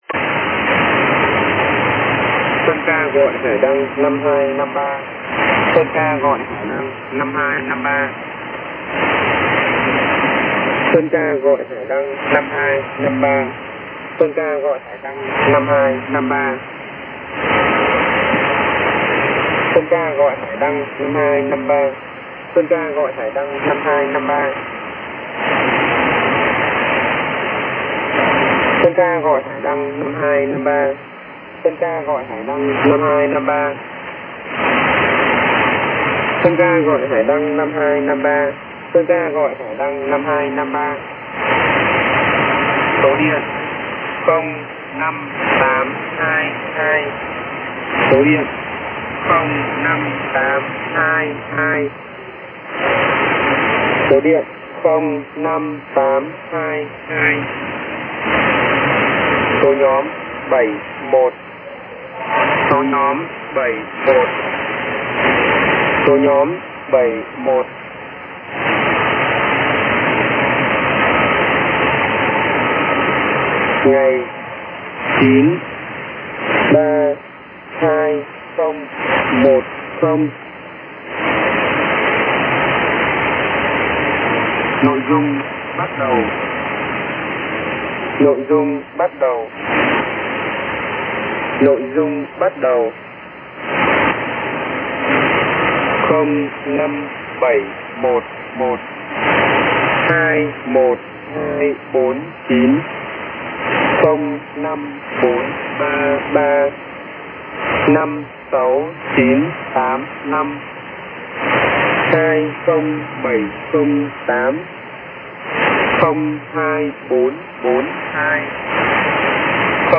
As mentioned above, the first format received on 02/21/2010 was a female voice (YL), in Vietnamese language (VT), and consisted of 5 figure number groups (5f).
63 message groups.